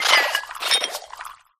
Grito de Sinistcha.ogg
Grito_de_Sinistcha.ogg